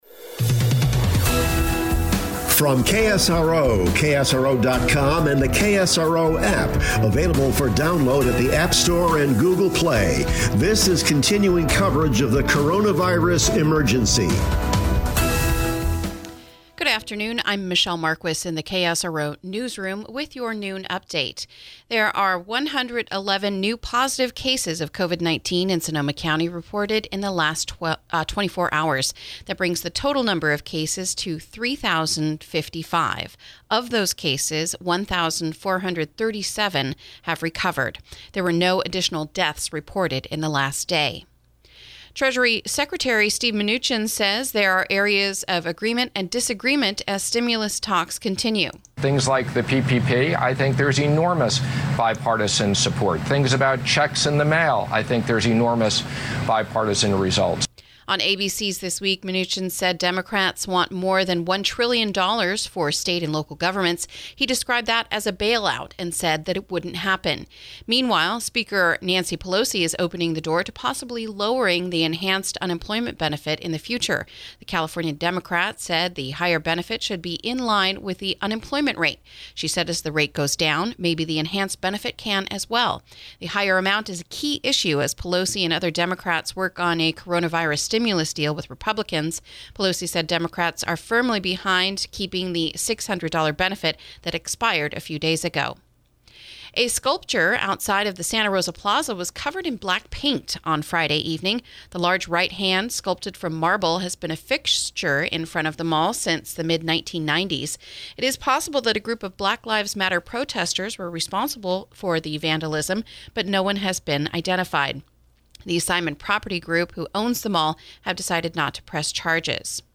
reports